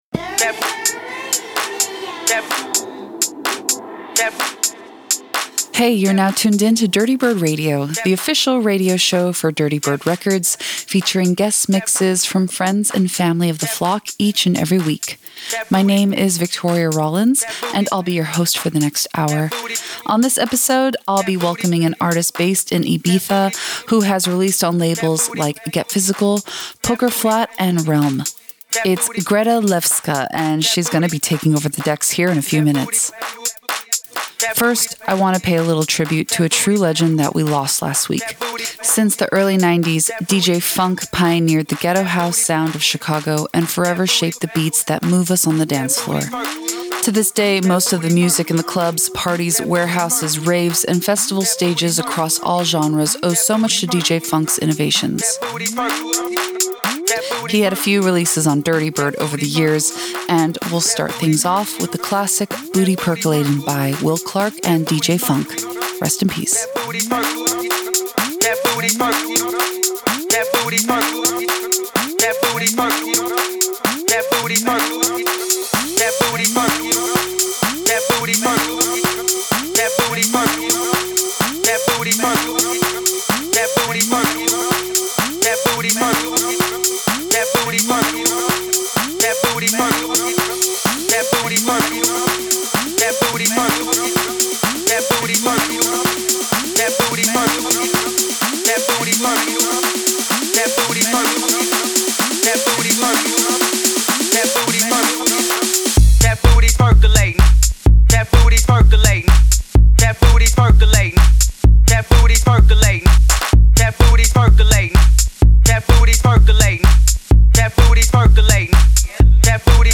VR warm-up set